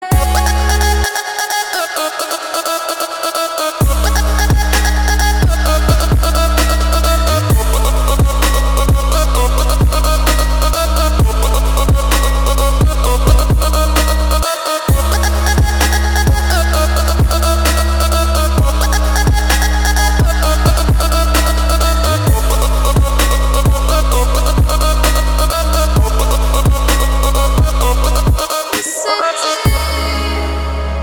• Качество: 320, Stereo
Electronic
мощные басы
без слов
Trap